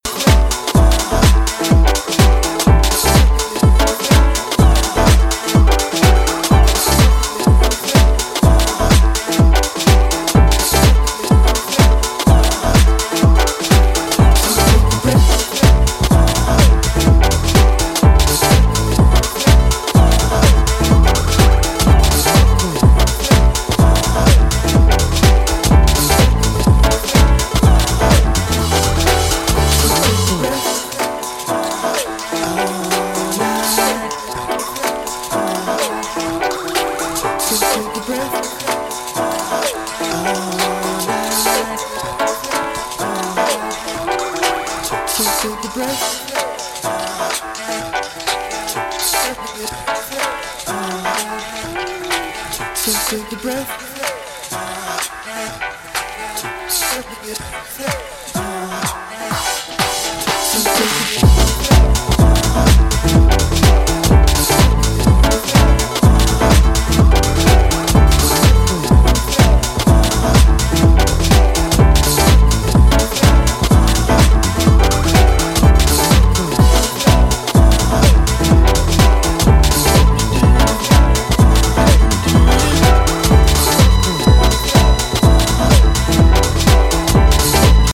Styl: Disco, House, Breaks/Breakbeat